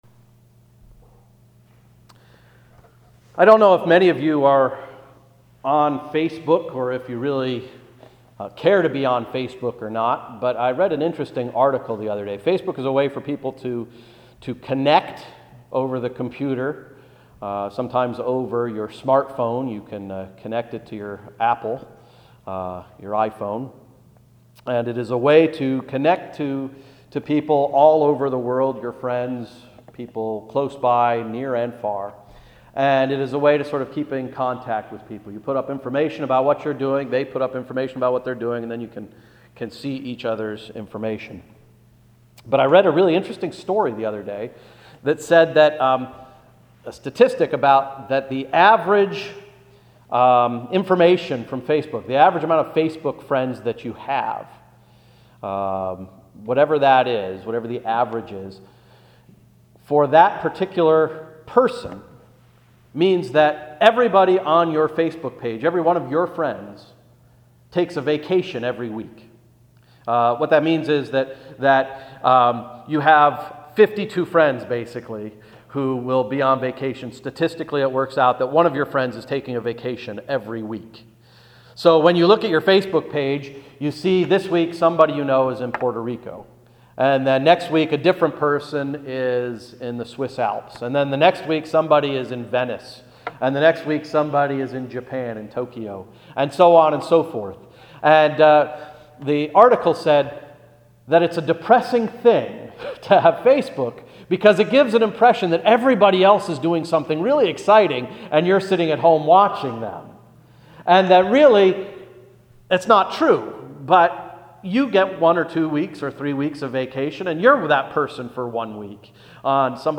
Sermon of November 16, 2014–“Risk”